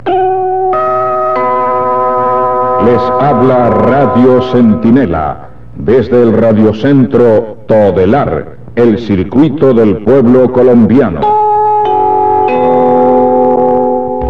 Identificación-Radio-Centinela-1982.mp3